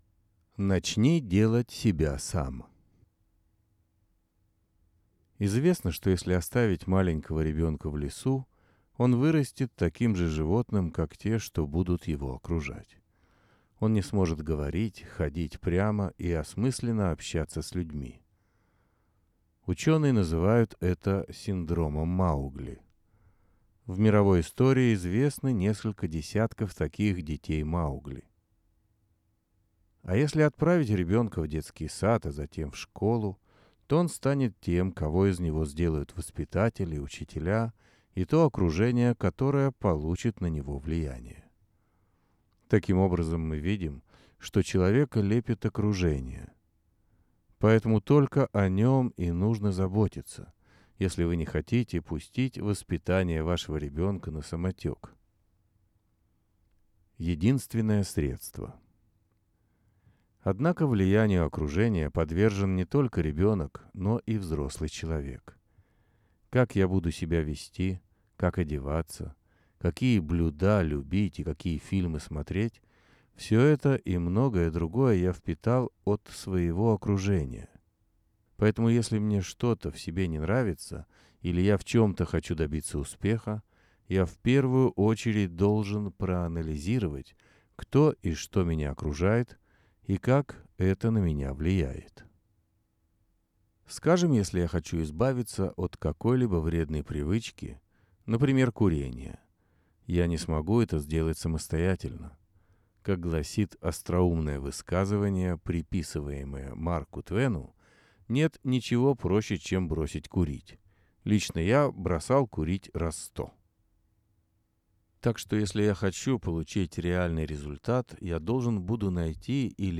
Аудиоверсия статьи